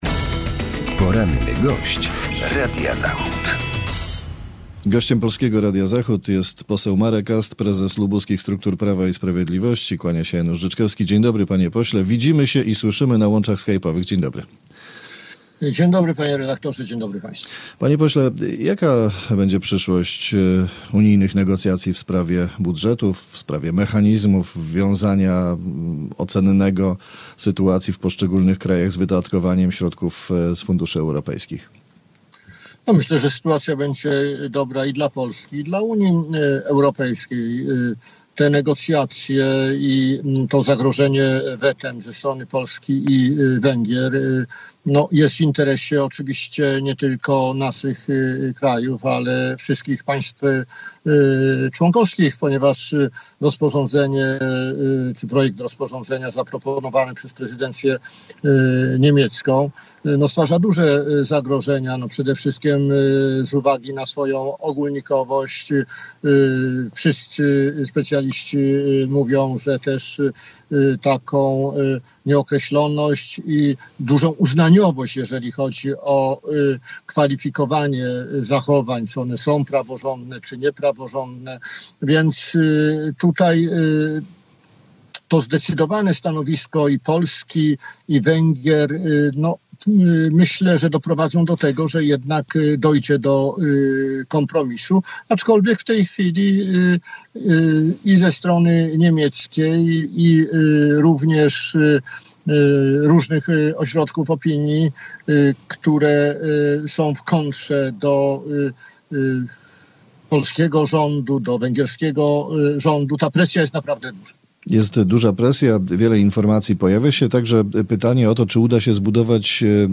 Z posłem PiS, prezesem partii w regionie lubuskim rozmawia